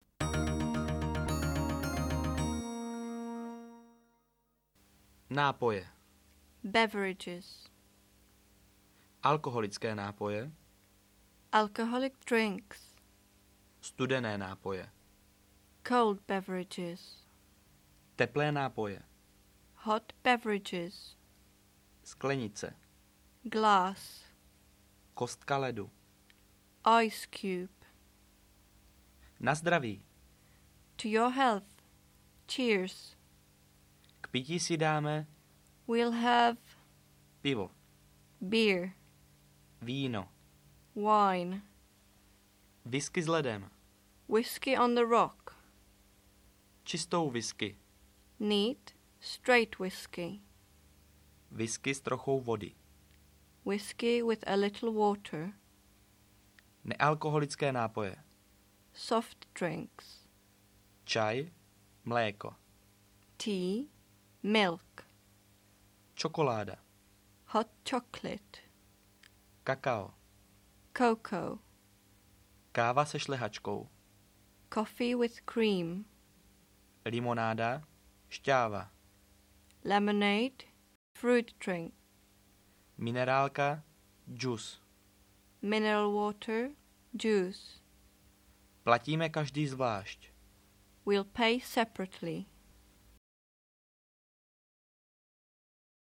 Angličtina - cestovní konverzace audiokniha
Ukázka z knihy